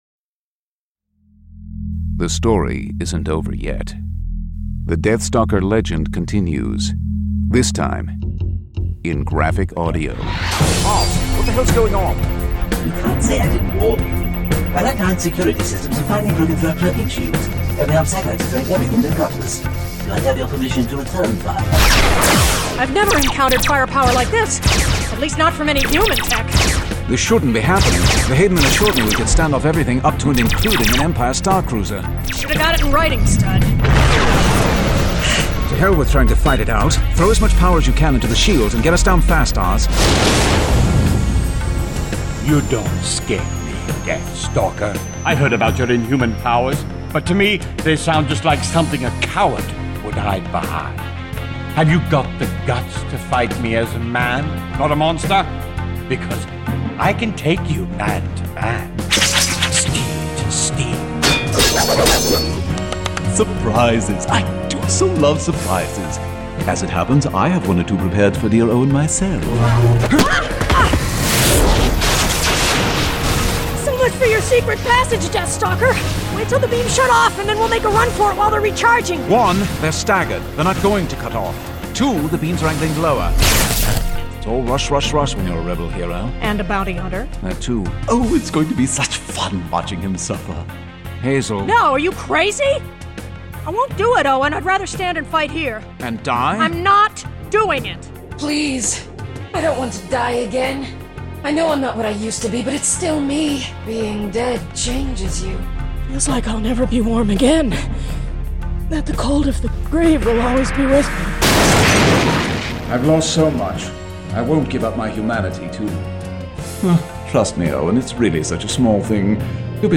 Full Cast. Cinematic Music. Sound Effects.